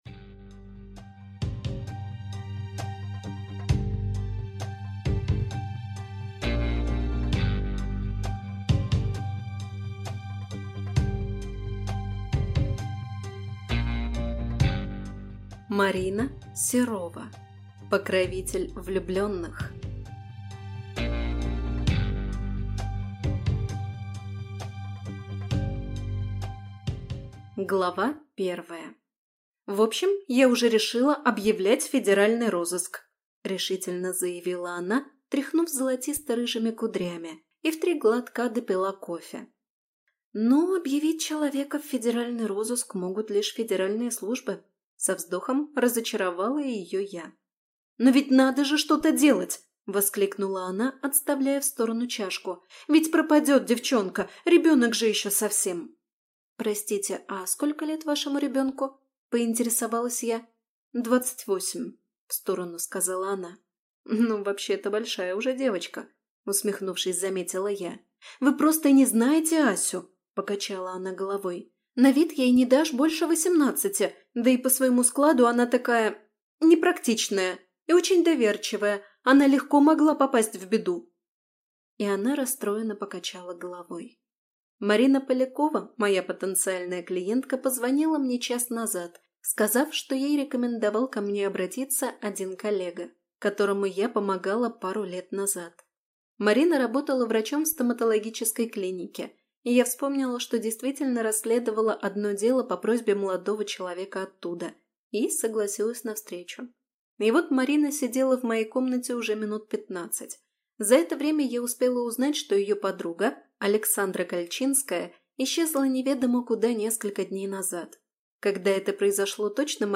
Аудиокнига Покровитель влюбленных | Библиотека аудиокниг